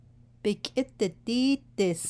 There is also a red button which, when clicked, will allow you to hear a recording of a speaker saying the sentence.